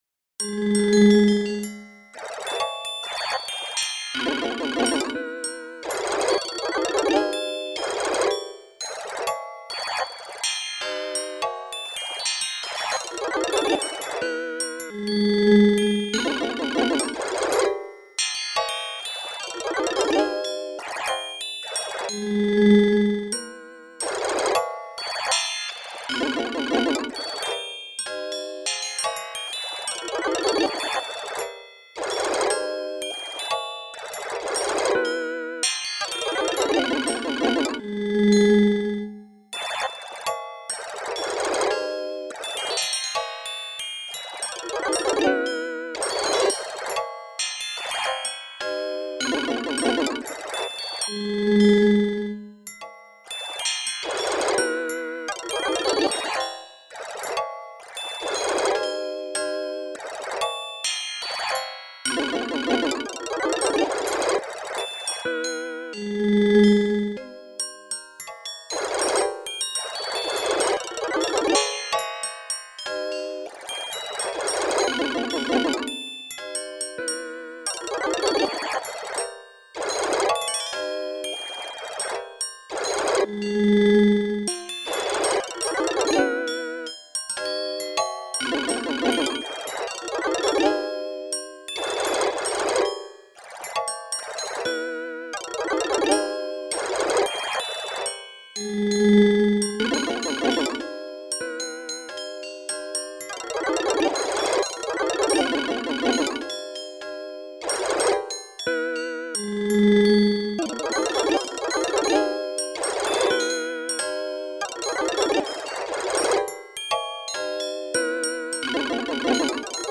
Four machine performances
These four recordings were all created using the Assistant Performer alone, without a live performer.
1. speed as notated in the score, minimum ornament chord duration set to 1 millisecond.